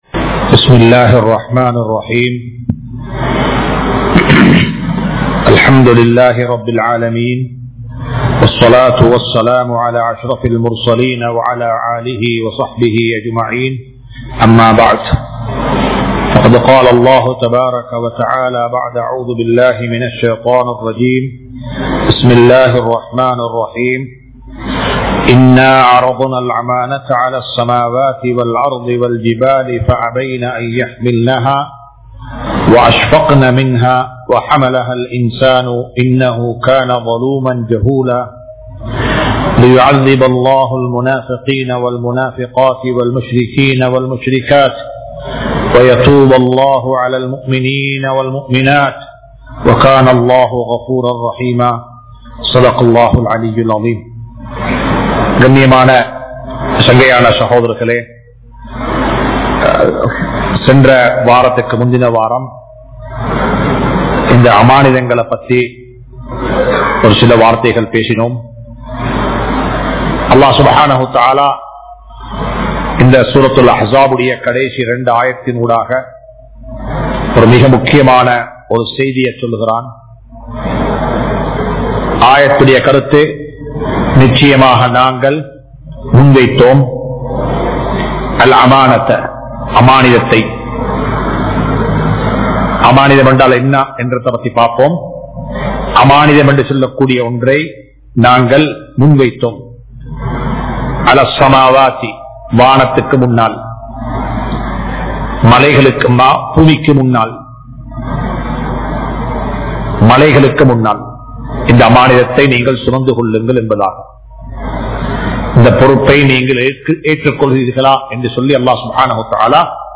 Amaanitham | Audio Bayans | All Ceylon Muslim Youth Community | Addalaichenai